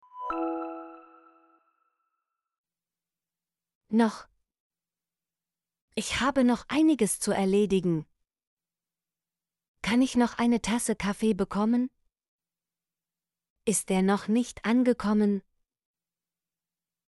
noch - Example Sentences & Pronunciation, German Frequency List